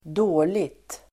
Uttal: [²d'å:li(k)t]